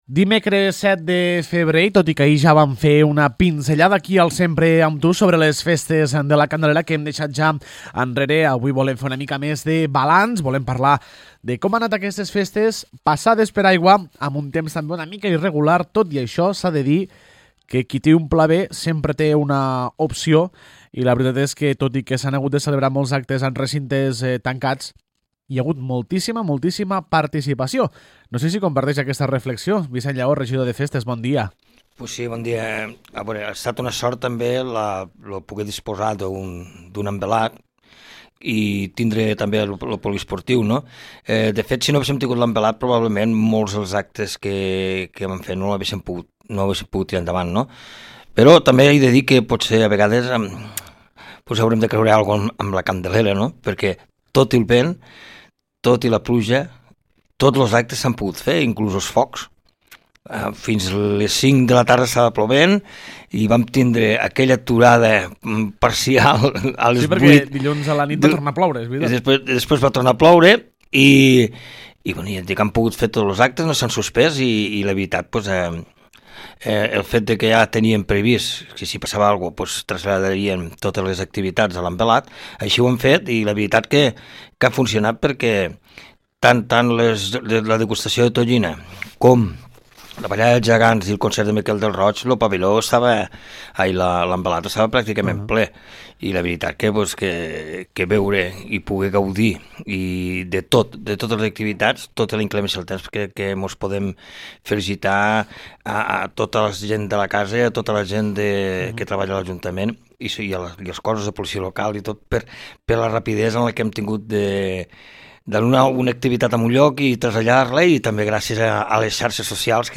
El regidor de Festes, Vicenç Llaó, ens acompanya per fer un balanç més ampli de la Festa Major de la Candelera 2018.